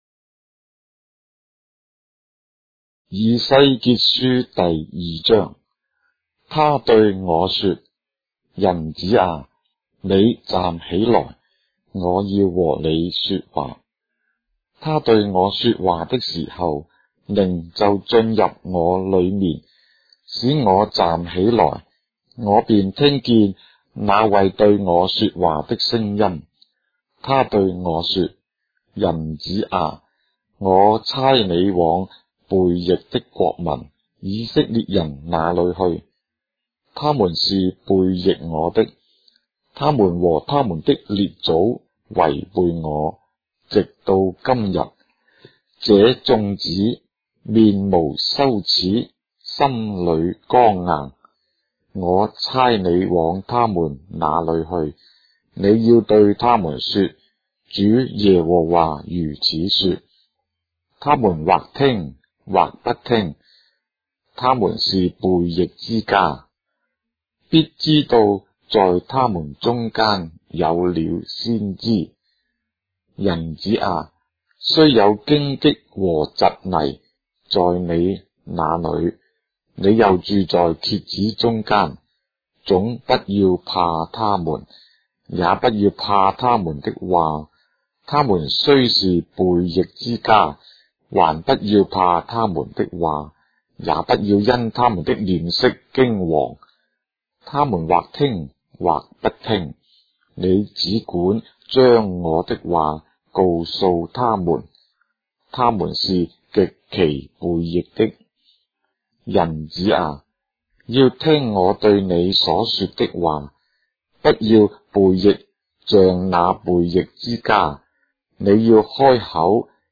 章的聖經在中國的語言，音頻旁白- Ezekiel, chapter 2 of the Holy Bible in Traditional Chinese